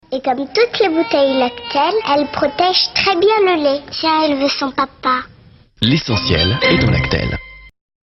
VOIX OFF SIGNATURE
2. LACTEL évident, affirmatif